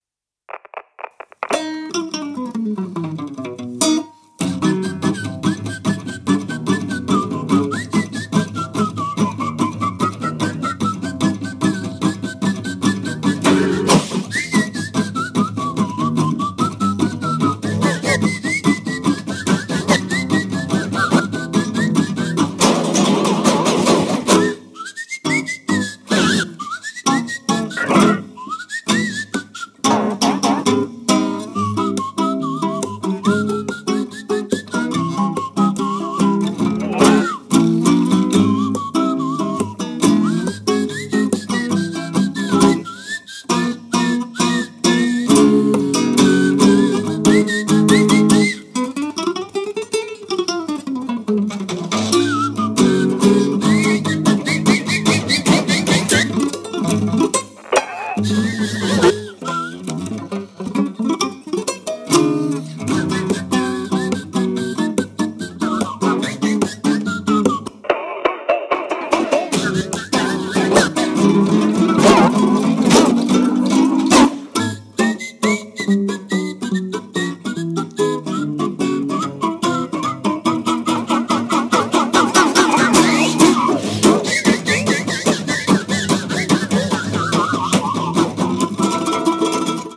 過激な部分を持ちながらも、ユーモラスでポップな感性が非常にイイですね！